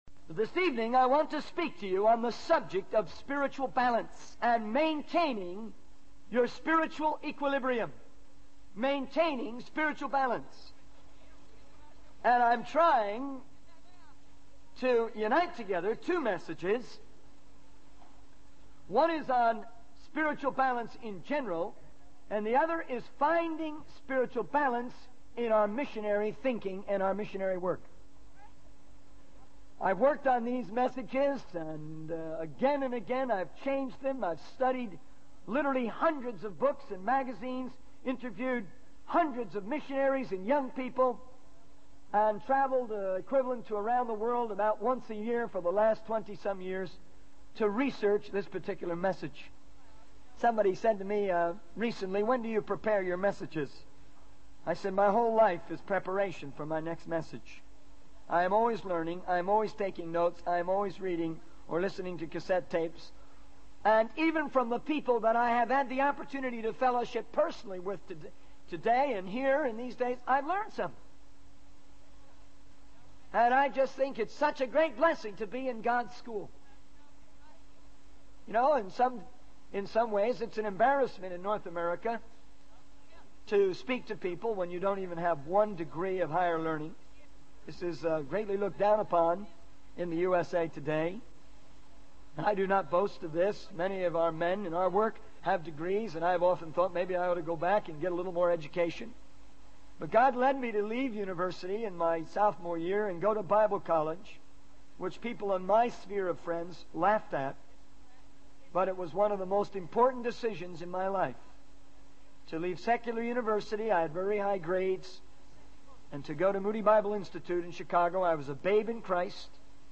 In this sermon, the speaker discusses the challenge of finding a balance between living a simple lifestyle and using modern methods in world missions. He shares his personal struggle with this issue, including the use of technology like television and computers in their ministry. The speaker emphasizes the importance of spiritual balance and not conforming to a cookie-cutter evangelical mindset.